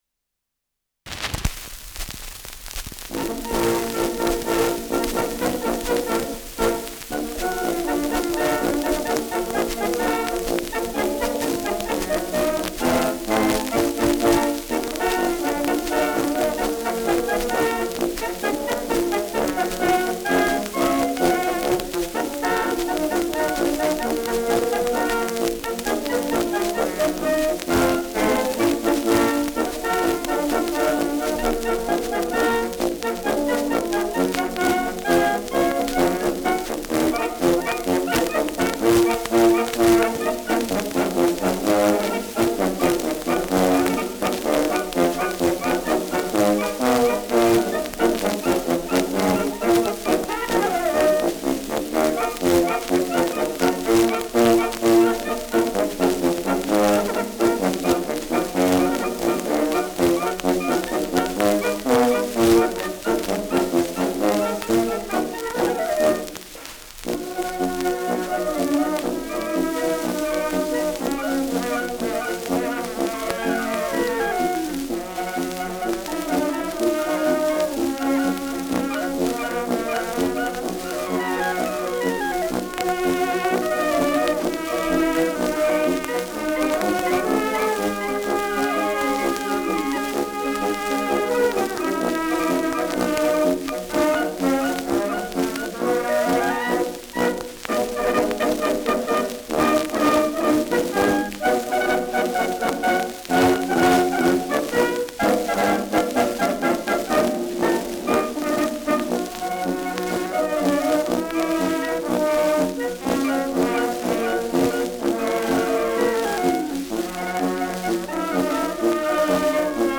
Schellackplatte
Schützenkapelle* FVS-00006